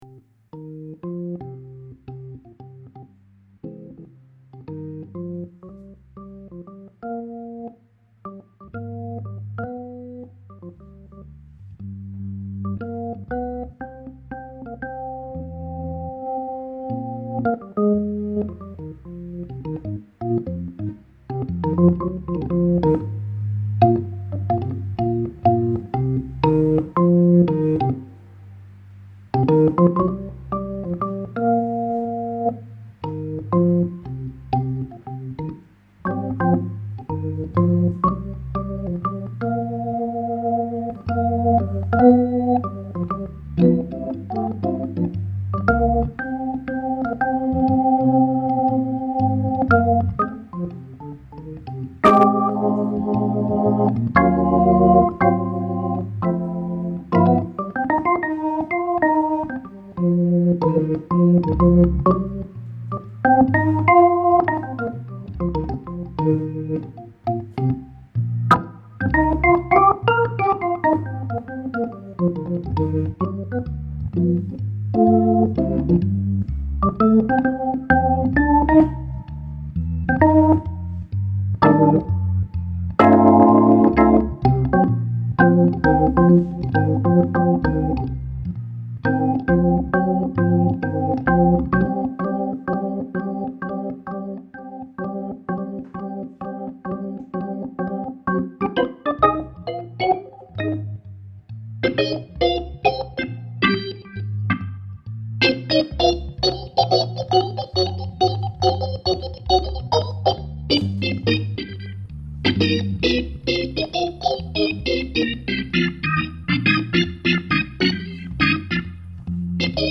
la procedure était simple...enregistrement audio et midi du new B sur la leslie 925 puis ....envoi du midi de la sequence dans le B4 lui meme branché sur la meme cabine avec la pedale combo trek II...le B4 était regle sur la line box ..logique puisqu'envoyé dans une vraie leslie.
Je me suis un peu avancé car hier je n'avais pas essyé l'un derriere l'autre ni enregistré....bien sur comme je regle les presets du B4 a la souris au fur et a mesure...et que je cours a la pedale combo pour enclencher le fast....les 2 sequences sont un peu differentes....mais dans l'ensemble j'ai fais au mieux...le B4 est un peu moins fort que le new B....vous adapterez vos reglages pour les mettre au meme niveau d'ecoute (oui le B4 souffle un peu beaucoup je sais)
D'une manière générale, le B4 est plus sec.
Puis le clic aussi, qui fait pas très très naturel.